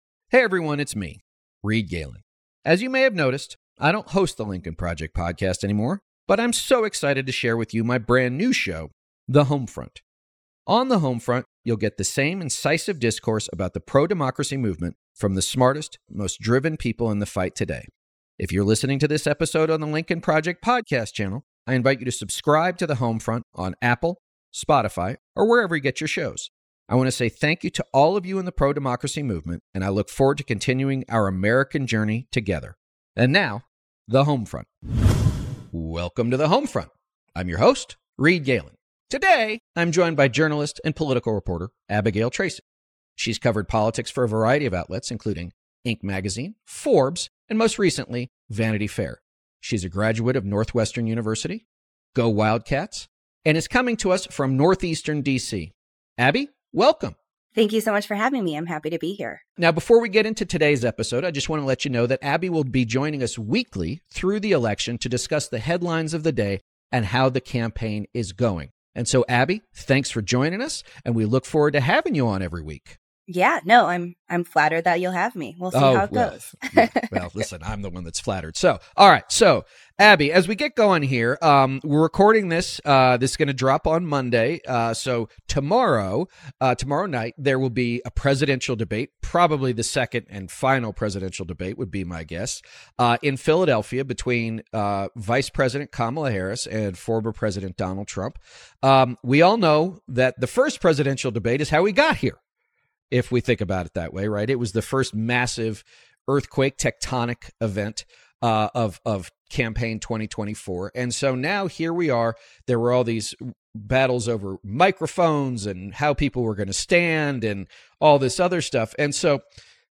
journalist and political reporter